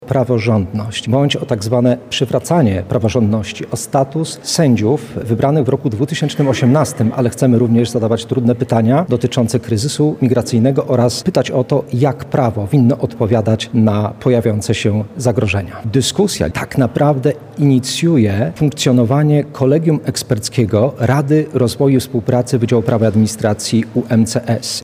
Debata dotycząca praworządności odbywa się w Auli Prawa i Administracji UMCS.